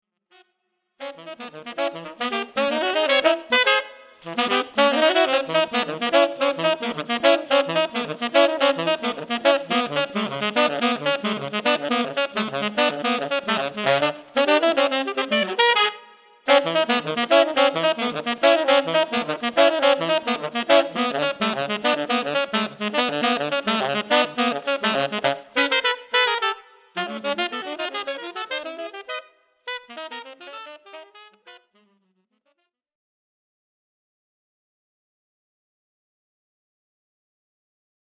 Saxes duet (AA or TT)